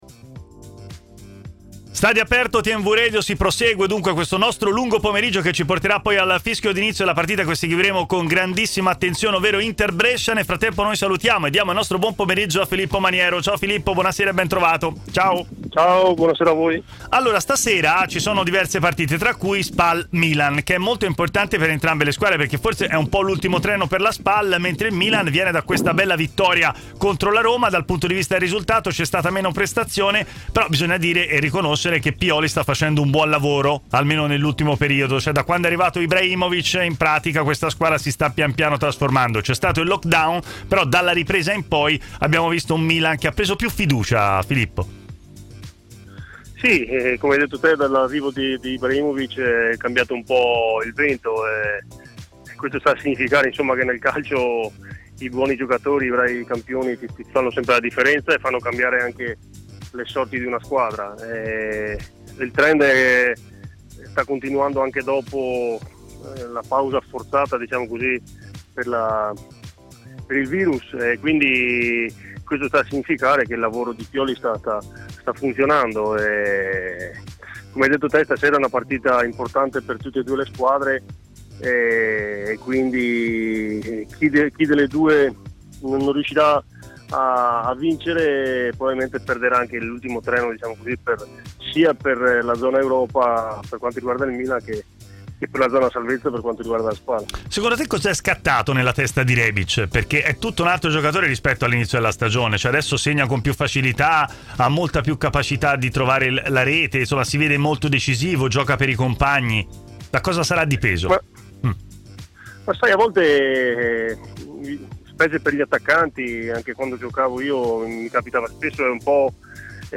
ha parlato ai microfoni di TMW Radio, intervenendo nel corso della trasmissione Stadio Aperto